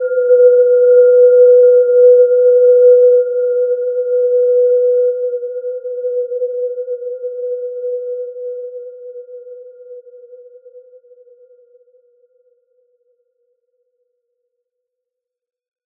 Gentle-Metallic-3-B4-mf.wav